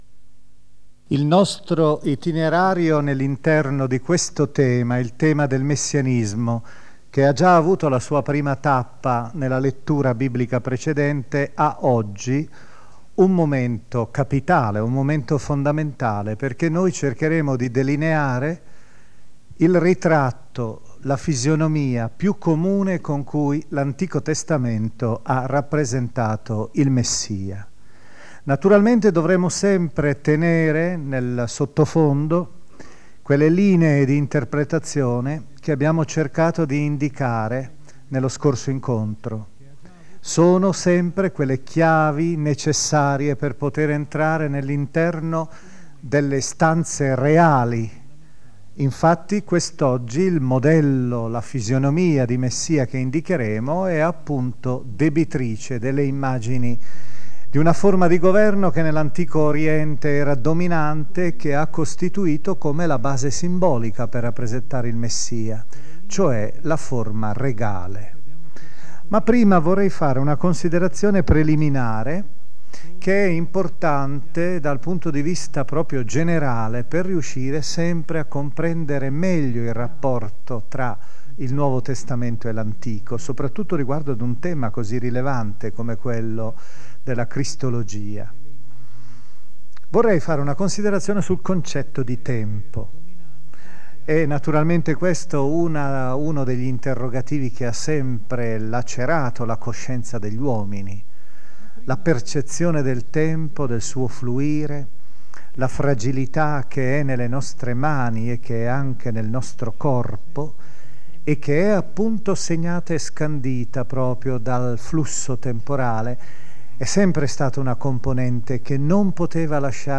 LECTIO BIBLICHE del card. Gianfranco Ravasi Il tempo e la storia secondo la Bibbia (scarica il file AUDIO)